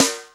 HR16B SNR 03.wav